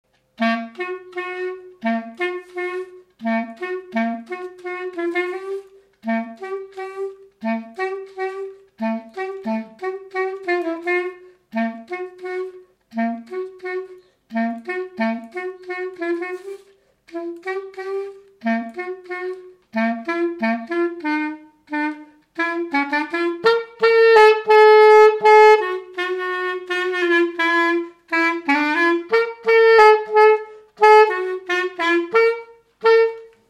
Polka
Résumé instrumental
danse : polka
Pièce musicale inédite